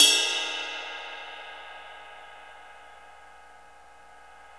CYM XRIDE 5I.wav